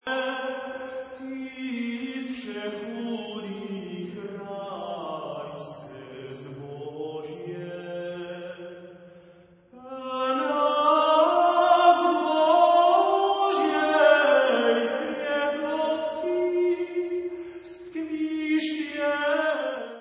Leich